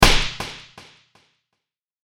ツッコミ打撃エコーあり.mp3